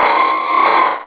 Cri d'Armaldo dans Pokémon Rubis et Saphir.